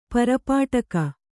♪ parapāṭaka